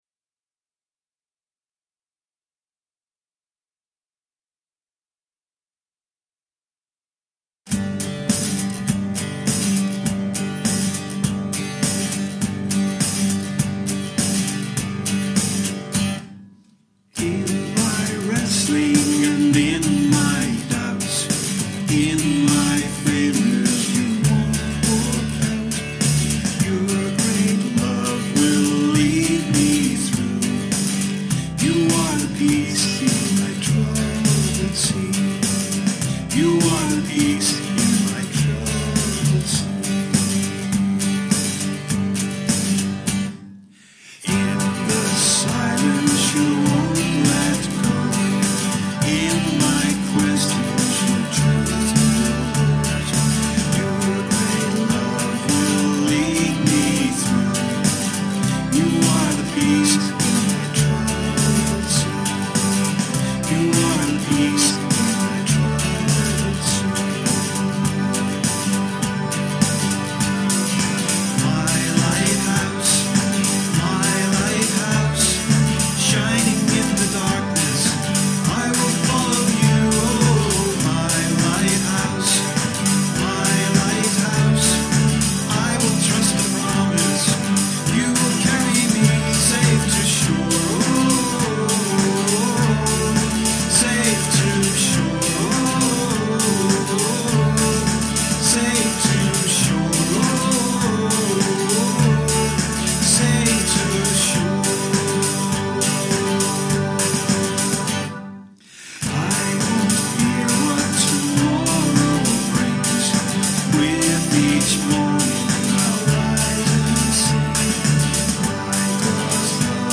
My-Lighthouse-Backing.mp3